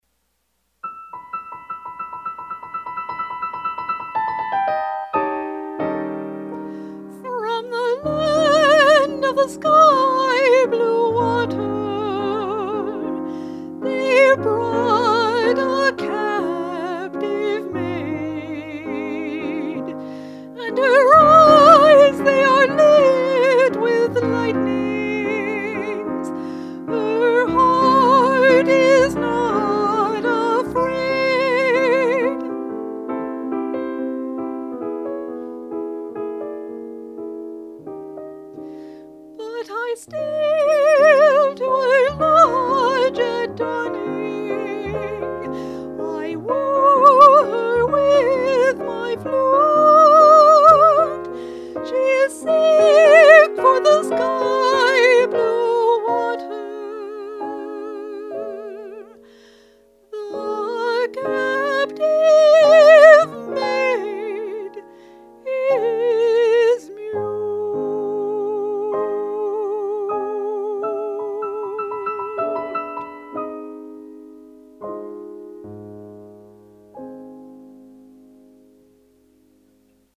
voice and piano